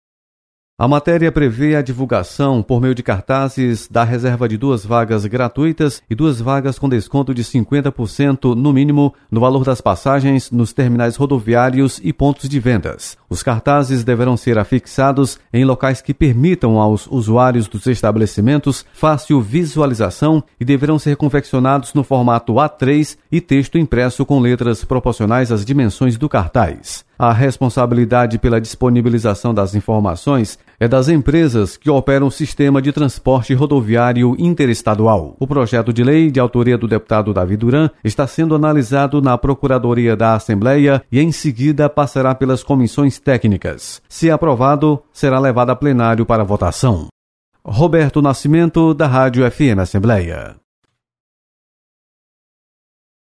Você está aqui: Início Comunicação Rádio FM Assembleia Notícias Projeto